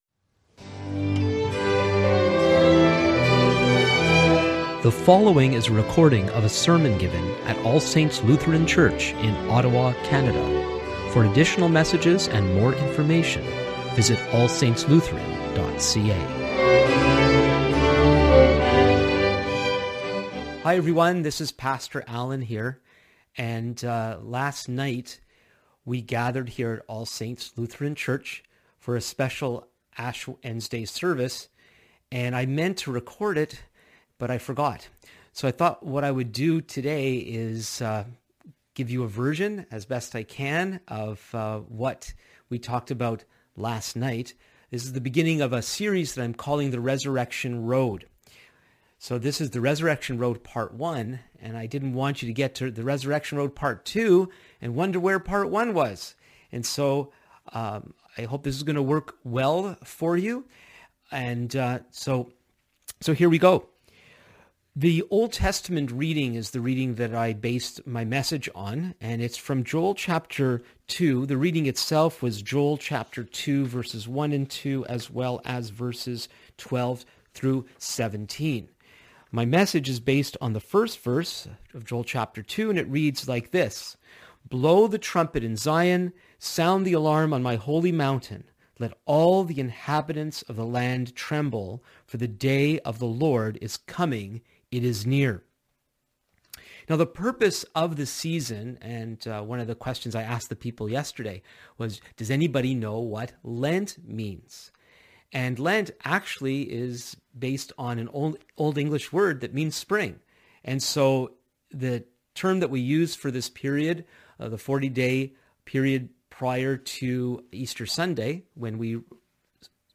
Sermons | All Saints Lutheran Church
Note: this is a special presentation recorded the day after, since the sermon wasn't recorded when first presented.